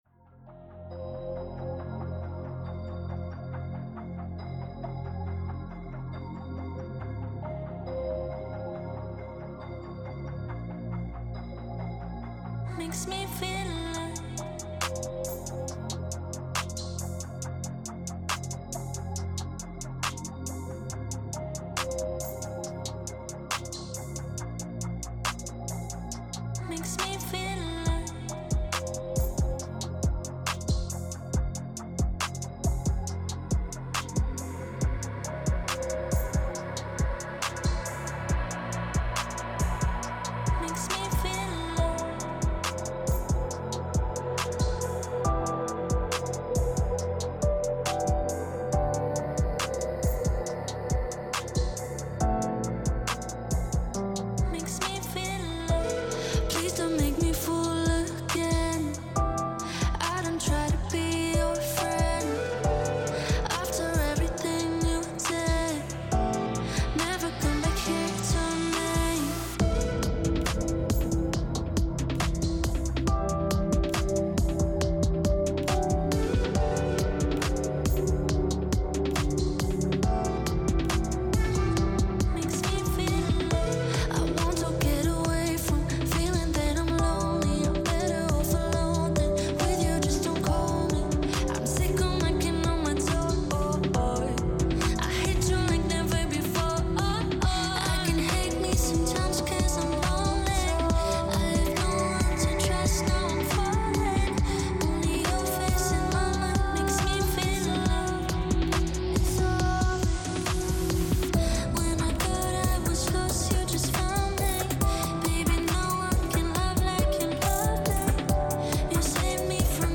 Tempo 138BPM (Allegro)
Genre Dark Trap Chill
Type Vocal Music
Mood Conflicting (Melancholic/Chill)